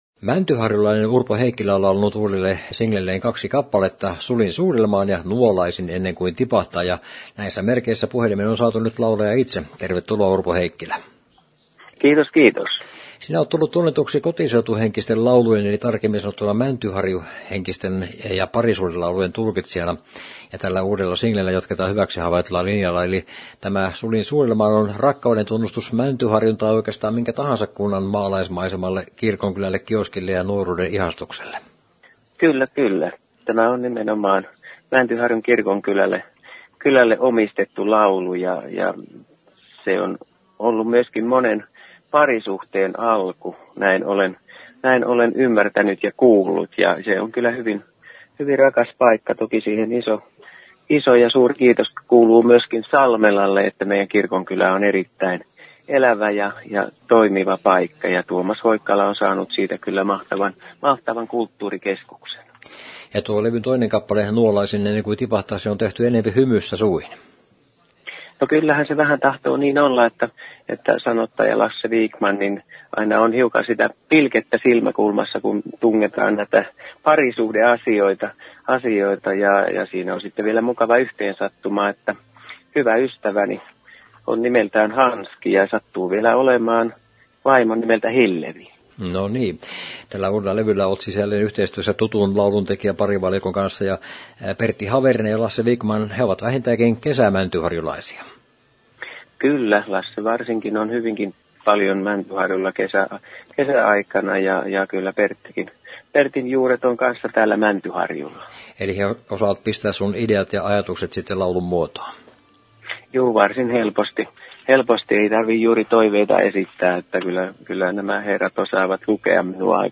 Haastattelu, Henkilökuvassa, Viihdeuutiset, Yleinen